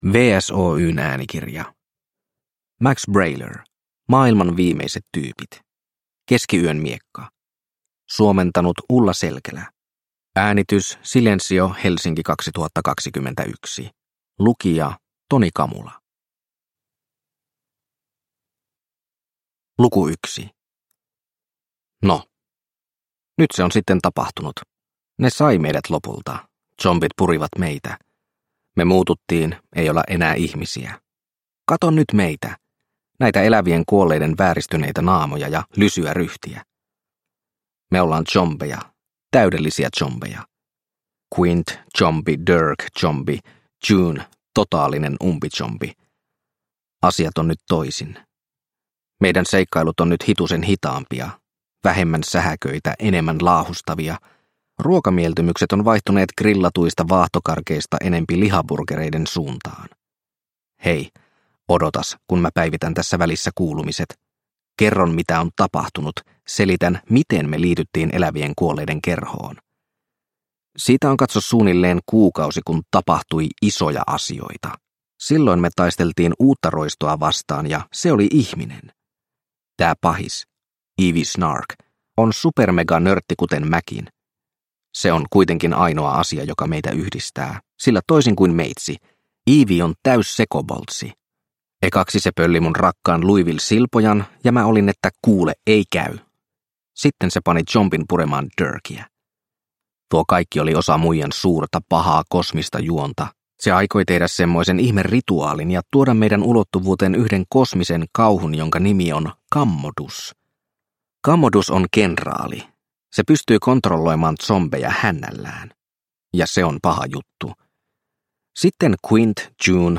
Maailman viimeiset tyypit - Keskiyön miekka – Ljudbok – Laddas ner